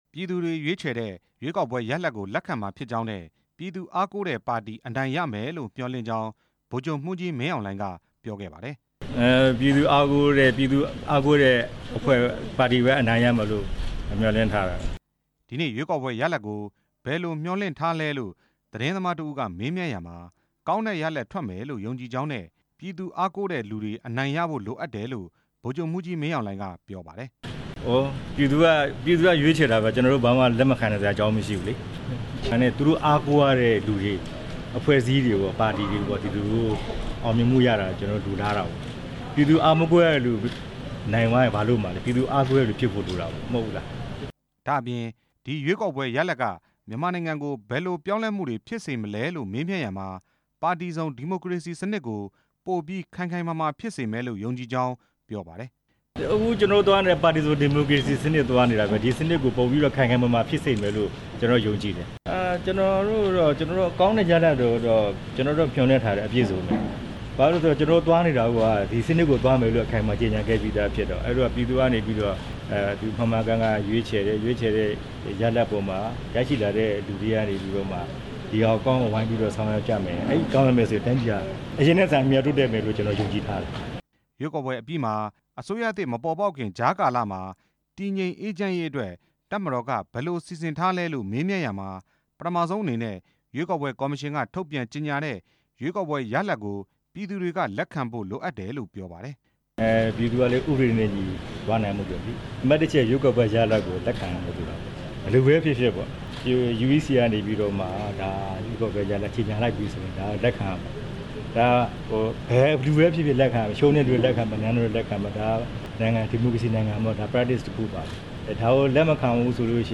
ကာကွယ်ရေးဦးစီးချုပ် (ကြည်း) ရုံးရှိတဲ့ နေပြည်တော် ဇေယျာသီရိမြို့နယ်က အနော်ရထာမဲရုံ အမှတ် ၁ မှာ လာရောက် မဲပေးစဉ် သတင်းထောက်တစ်ဦးက ရွေးကောက်ပွဲမှာ အတိုက်အခံပါတီဖြစ်တဲ့ အန်အယ်လ်ဒီပါတီ အနိုင်ရရှိမယ်ဆိုရင် ဘယ်လိုသဘောထားမလဲလို့ မေးမြန်းရာ ဗိုလ်ချုပ်မှူးကြီး မင်းအေင်လှိုင် က ဖြေကြားခဲ့တာဖြစ်ပါတယ်။